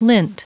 Prononciation du mot lint en anglais (fichier audio)
Prononciation du mot : lint